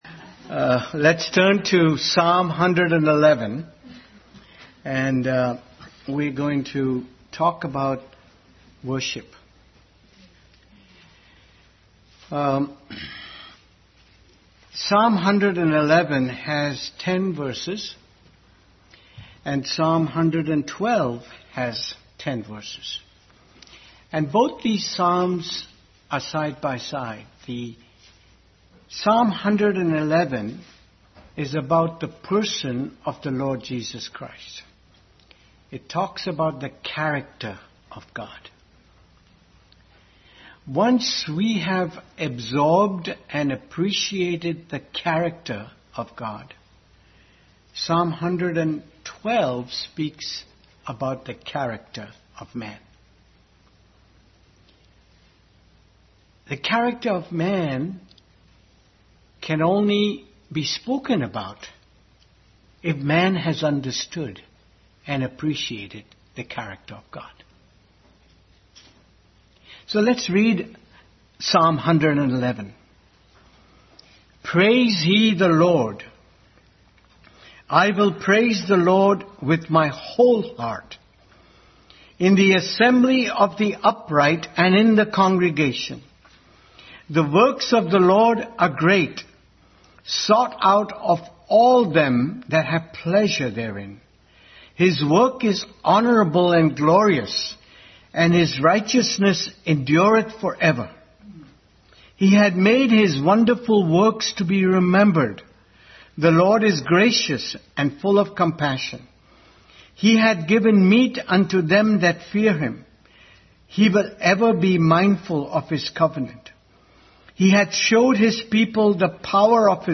Psalm 111 Service Type: Family Bible Hour Bible Text